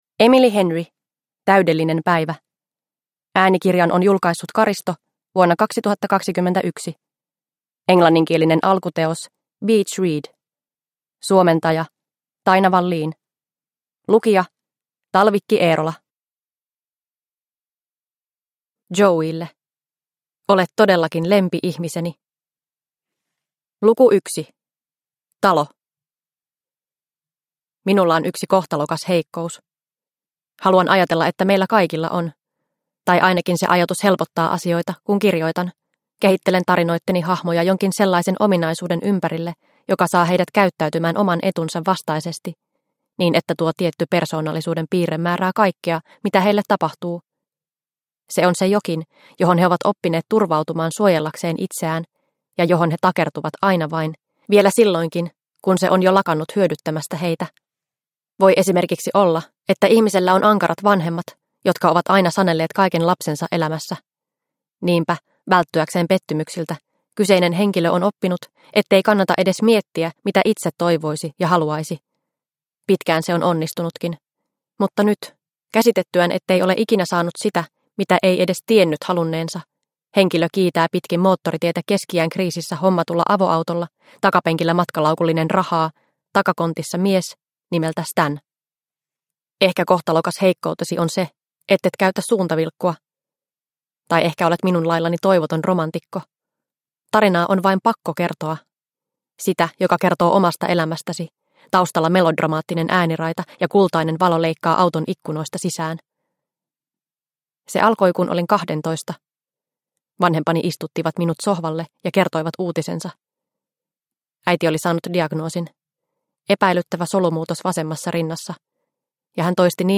Täydellinen päivä – Ljudbok – Laddas ner